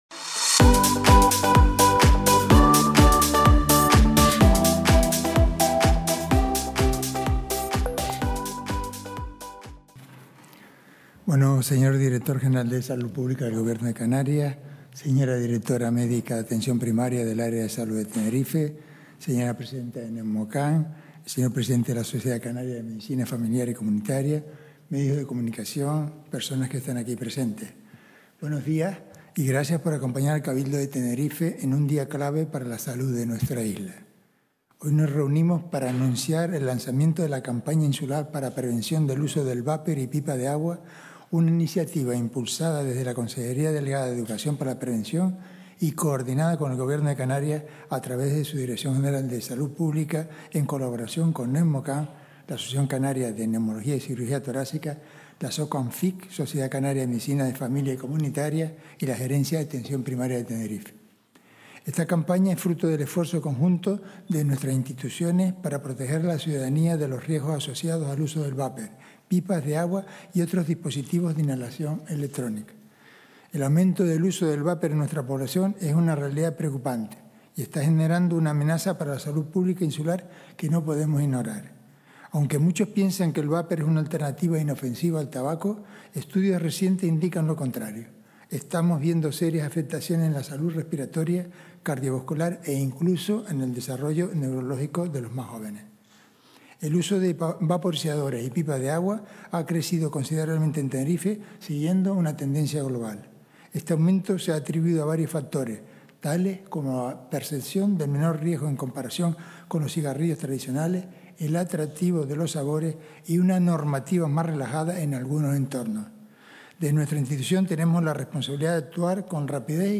Presentación